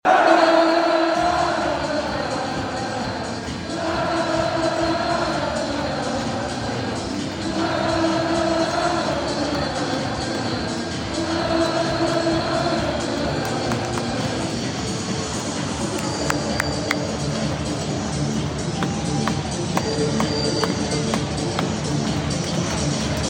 The stadium’s empty. But Bristol City fans? Still partying.